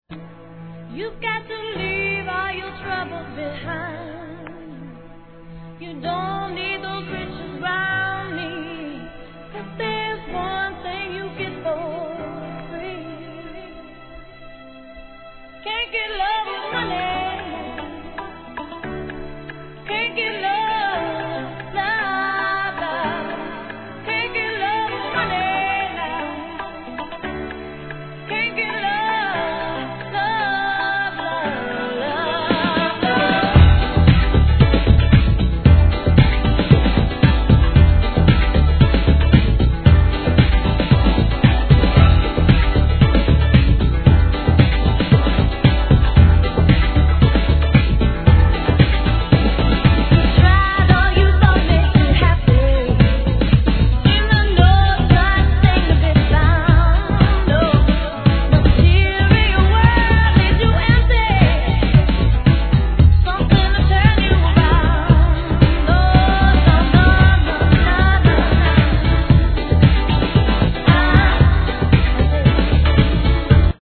HIP HOP/R&B
キーボードのメロディ〜に跳ねるBEATが最高に爽快なナンバー!